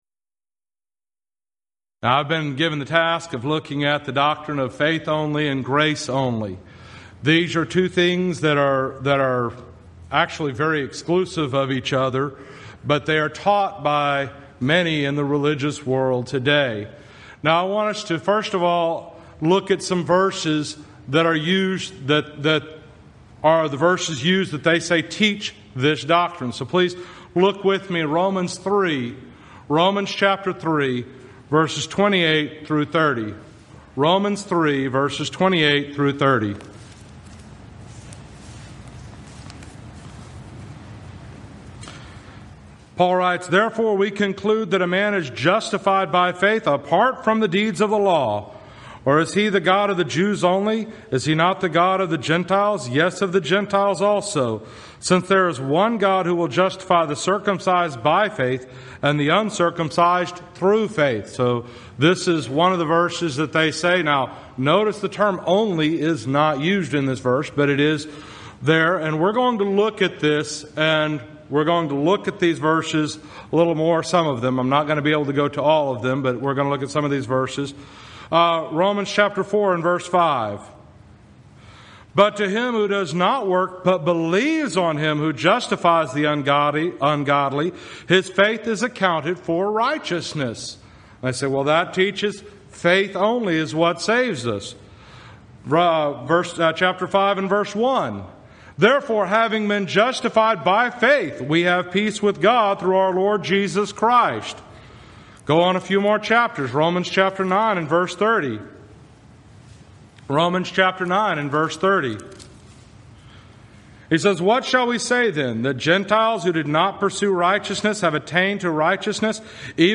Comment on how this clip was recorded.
Event: 2015 South Texas Lectures